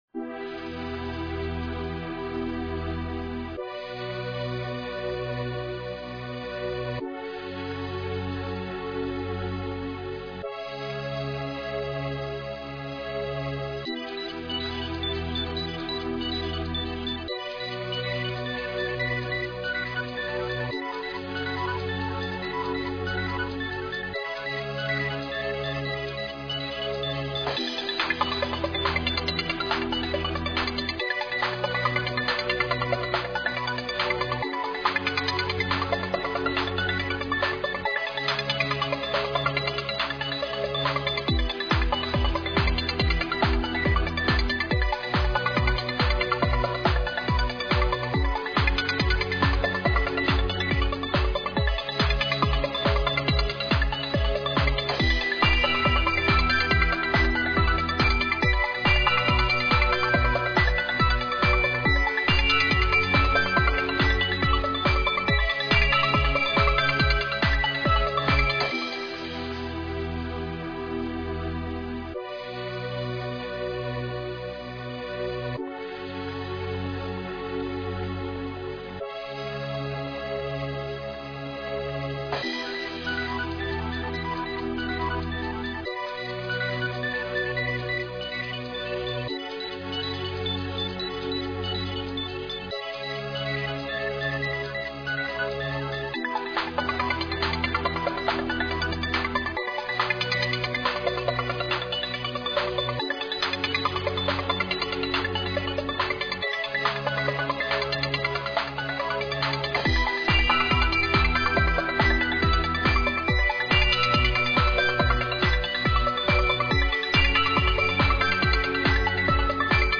Написание песни, создание саунд-трека Категория: Написание музыки
Необходимо сочинение песни, на тему интернета, типа как гимн, эта мызыка будет играть на удержании на линии при ожидании абонентом ответа оператора, можно ближе к классической, но особо не скучной, не сонной но и не раздражающей, не супер бодрой но современной.
Формат - mp3 mono